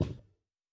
Ball Kick Distant.wav